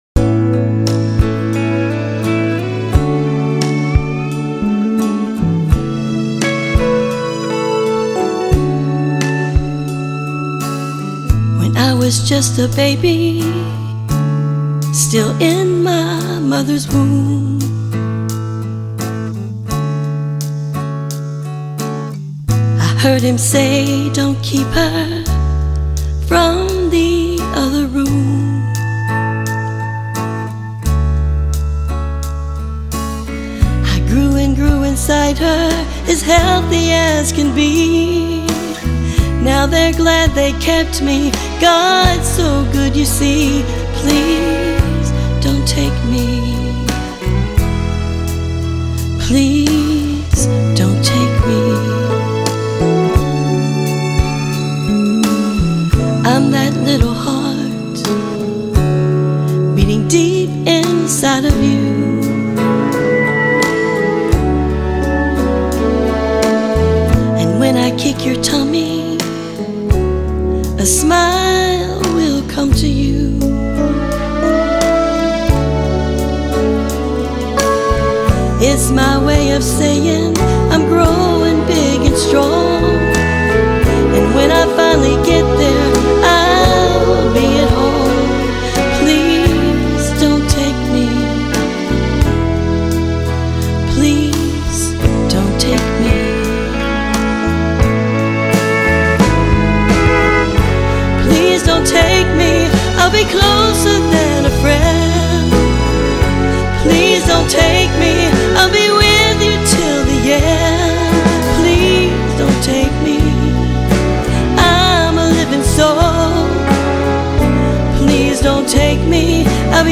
moving ballad
Lead Vocals
Backup vocals